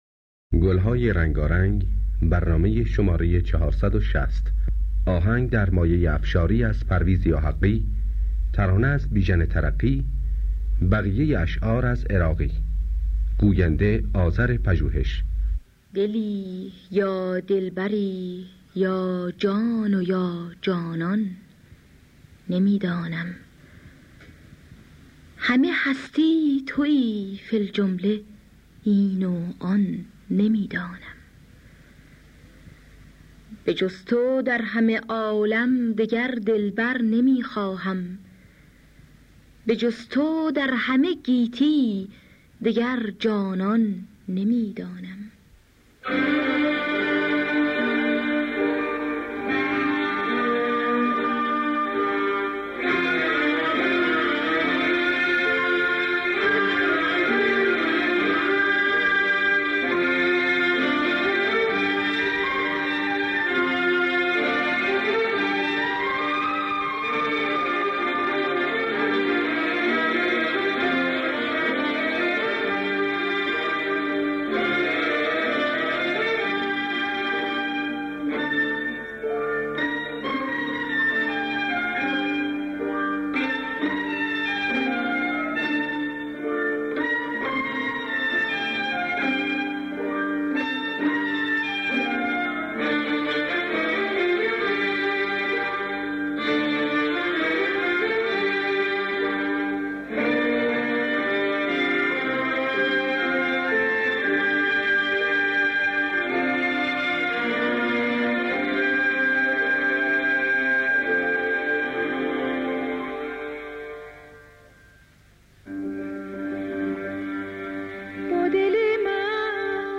در دستگاه افشاری